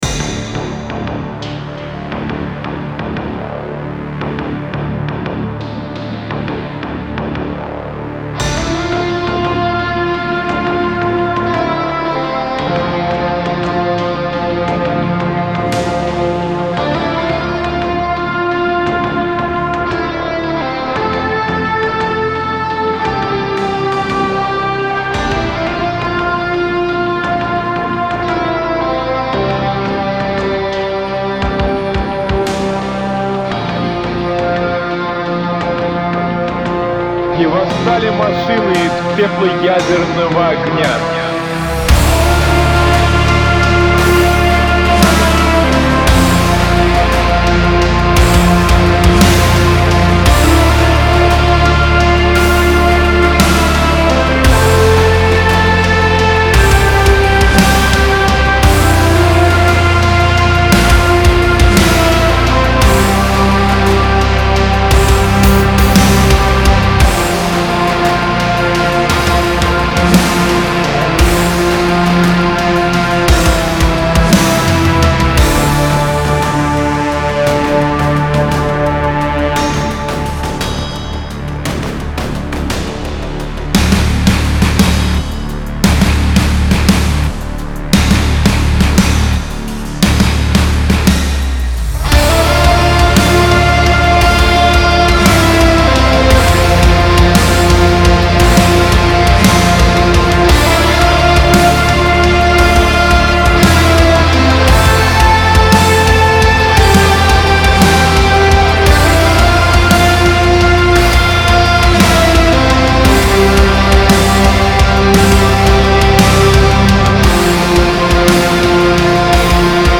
- Атмосфера: Мрачная, напряженная, постапокалиптическая.
- Инструменты: Синтезаторы, бас, вокал.
- Темп: Медленный, нарастающий.
- Эмоции: Тревога, напряжение, безысходность.